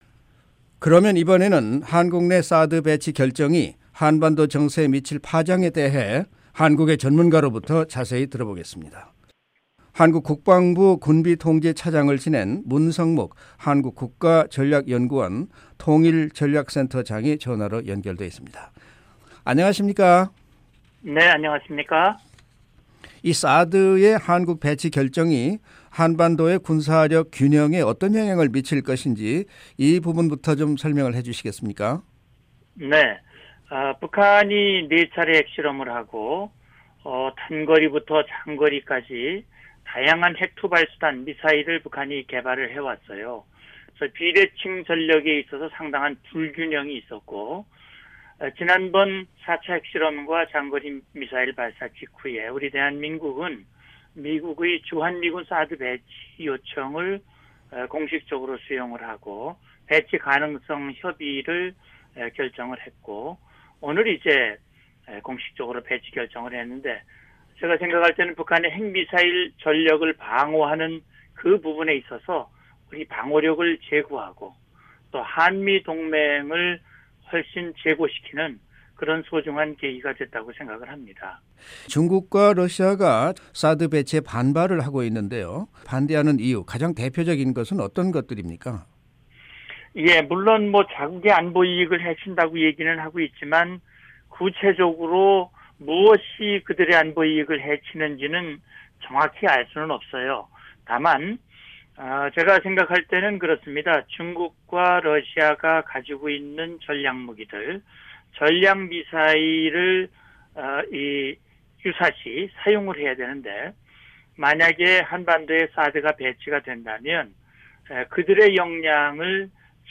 [인터뷰: